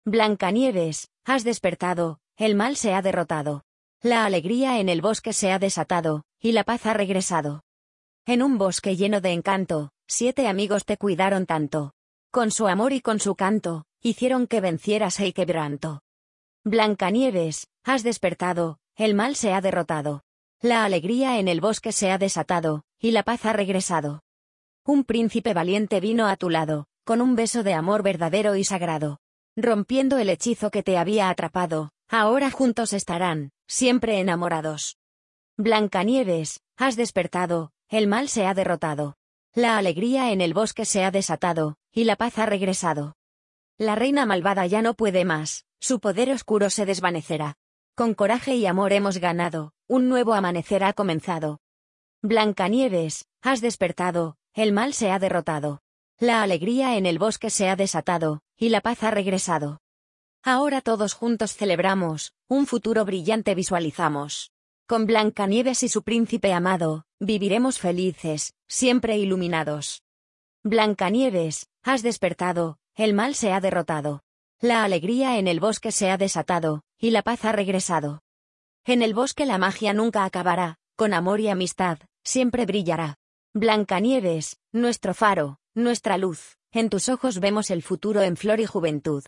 # Inicializar el motor de conversión de texto a voz